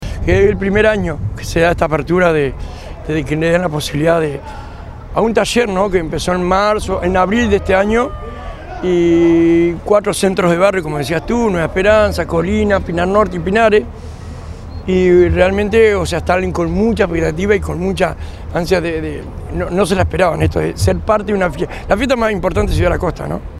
A orillas del arroyo Pando en El Pinar, Ciudad de la Costa celebró sus 29 años con la colocación de un escenario en el que hubo artistas locales, nacionales, una feria de emprendedores y diversas autoridades.